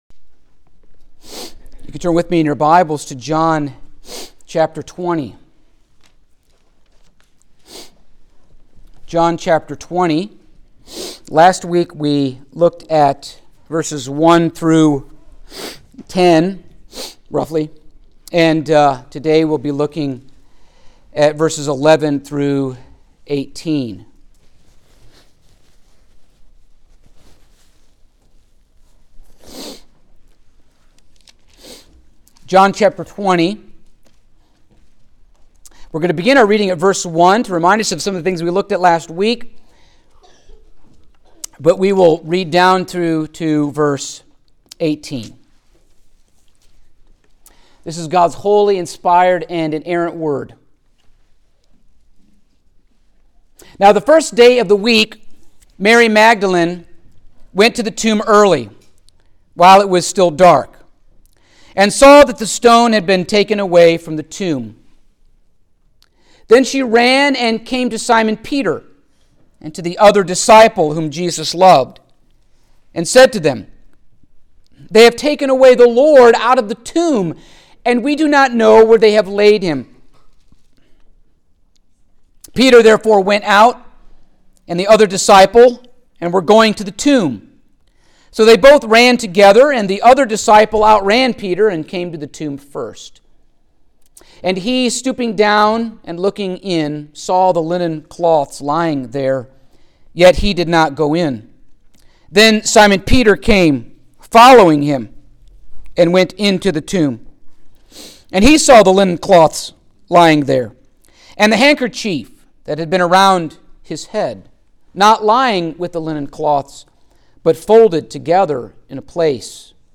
Passage: John 20:11-18 Service Type: Sunday Morning